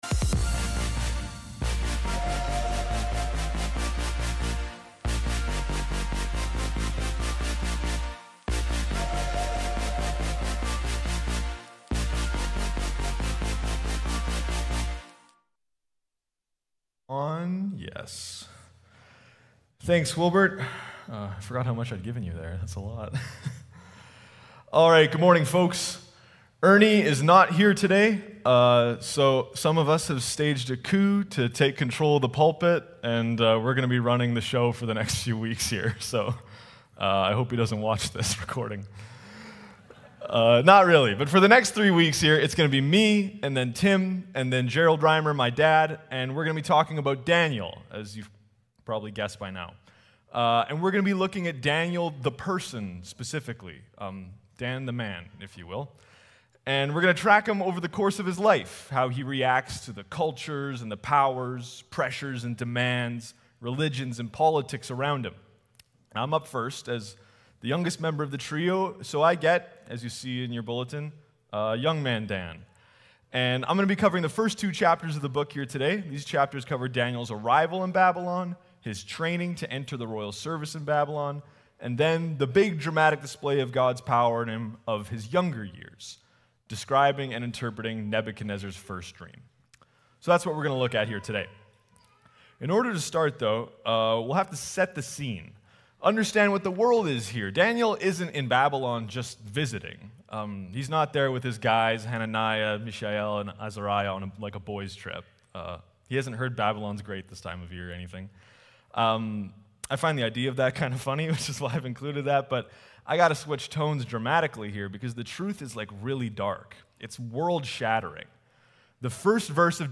Message
august-11-service.mp3